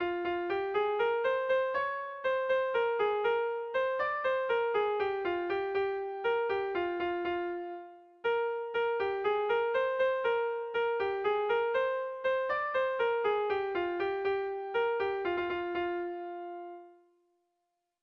Sentimenduzkoa
Zortzikoa, txikiaren moldekoa, 4 puntuz (hg) / Lau puntukoa, txikiaren modekoa (ip)
ABDB